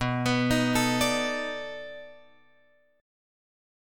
B 7th Sharp 9th